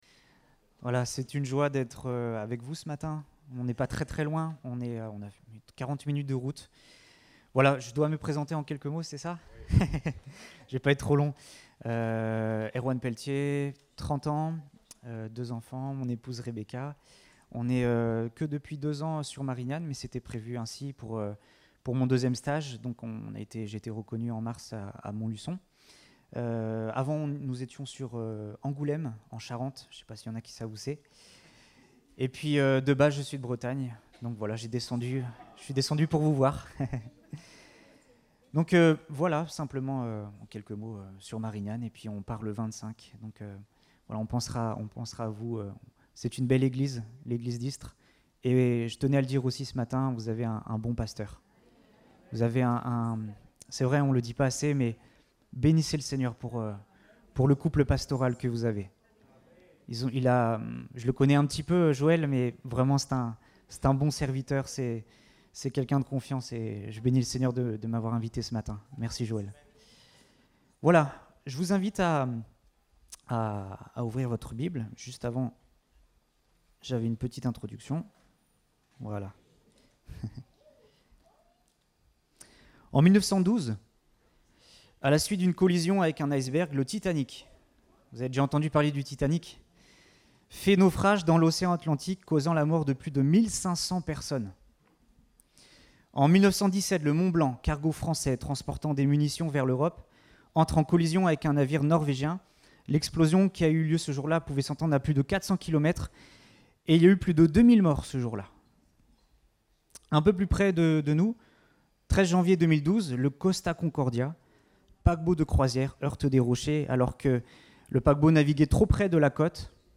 Date : 10 juillet 2022 (Culte Dominical)